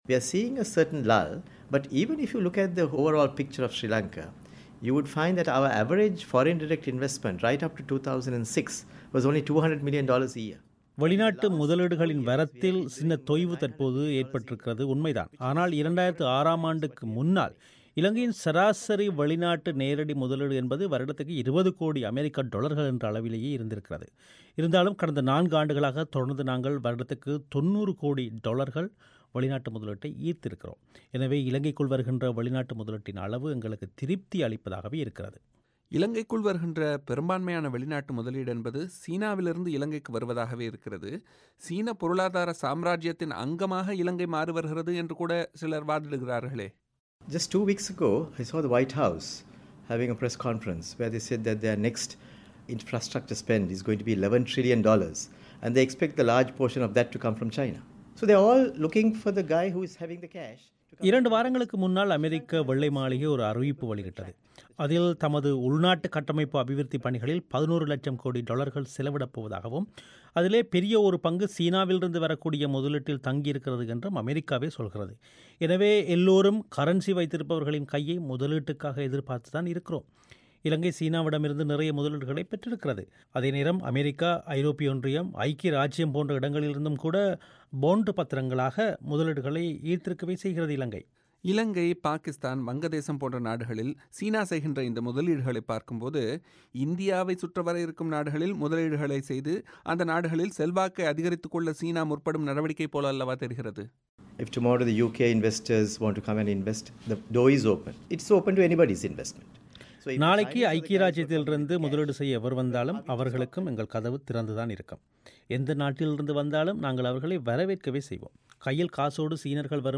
அவரது பேட்டியிலிருந்து சில முக்கிய பகுதிகளின் தமிழ் வடிவத்தை நேயர்கள் இங்கு கேட்கலாம்.